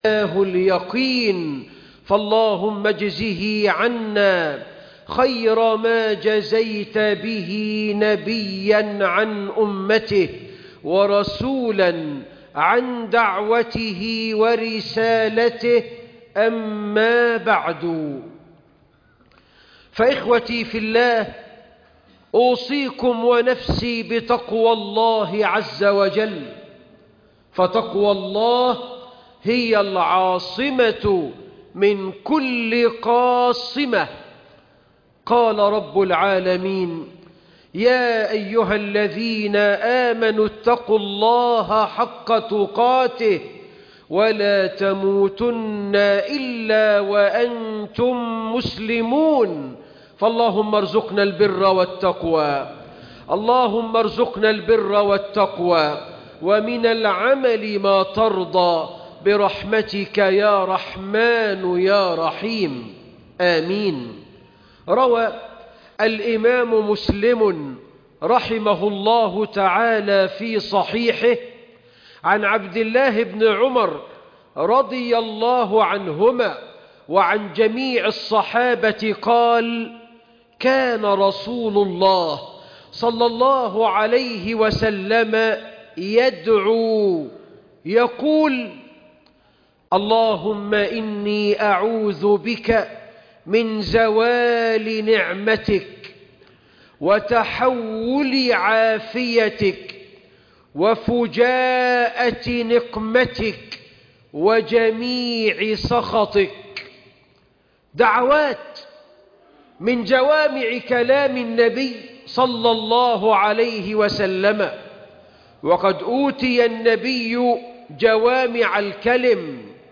أربع تعوذ منها رسول الله ﷺ خطب الجمعة